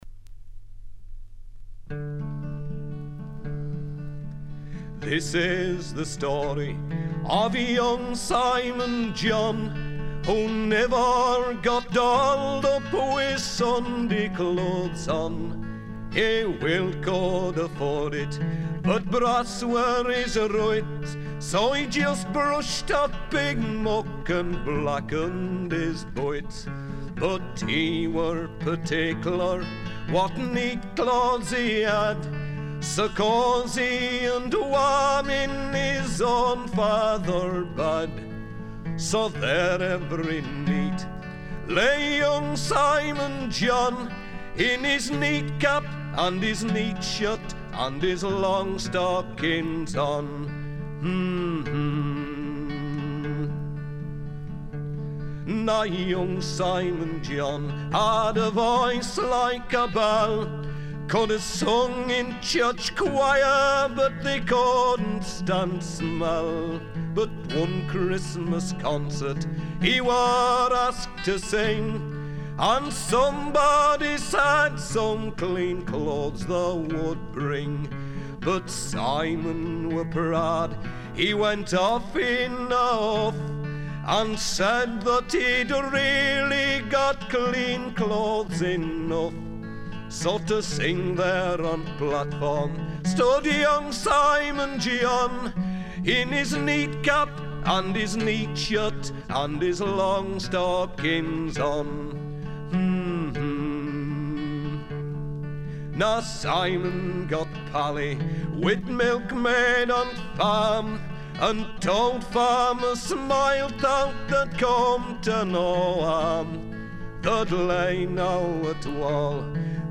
folk singer
his fine voice and lively interpretation of traditional English songs seemed to me to put him in the first rank of British singers.